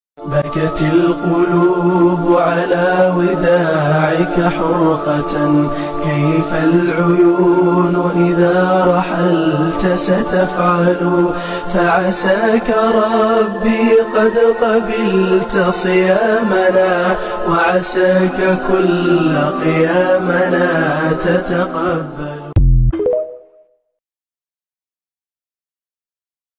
بكاء حزين جدا